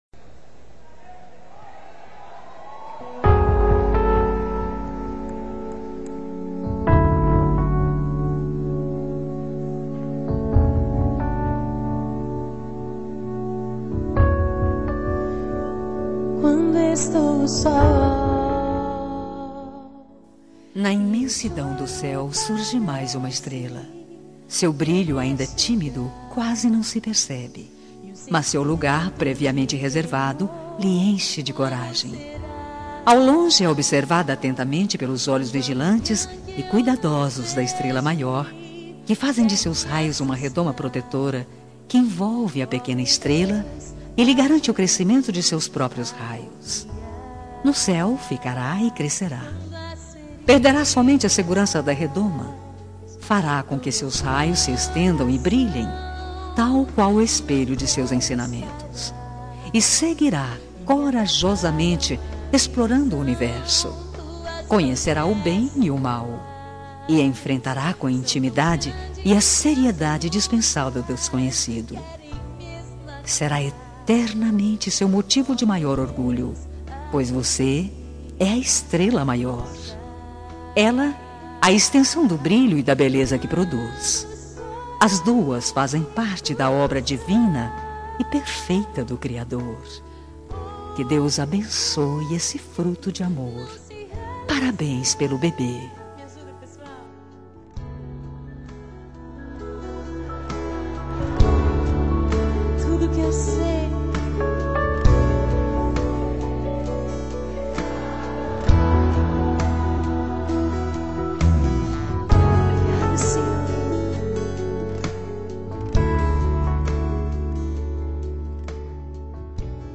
TELEMENSAGEM EVANGÉLICA MATERNIDADE
Voz Feminina